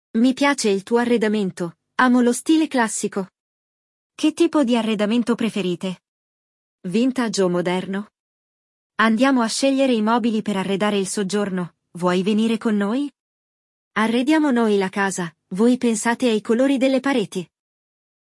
Neste episódio, vamos acompanhar duas colegas de trabalho que falam sobre um novo projeto para uma pessoa famosa.
Il dialogo